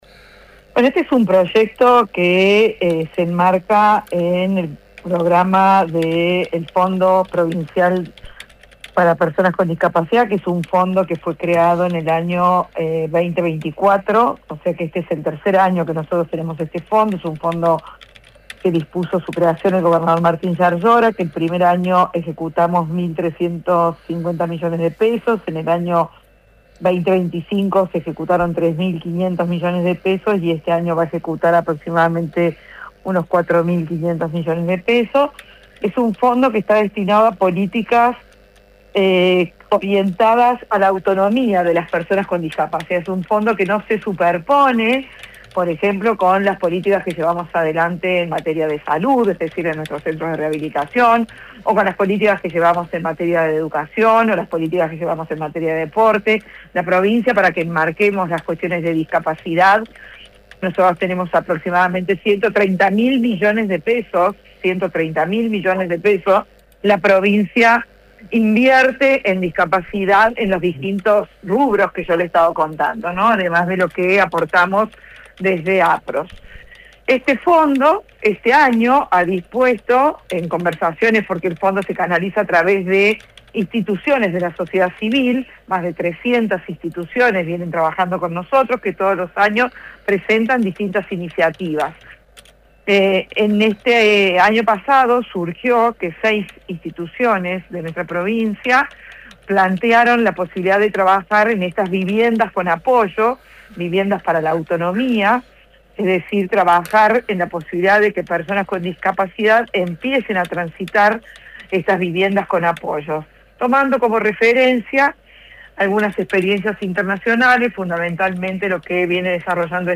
Liliana Montero, Secretaria general de Salud y Desarrollo Humano, dijo que se enmarca en programa de fondo para discapacidad destinado a políticas de autonomía de personas con discapacidad.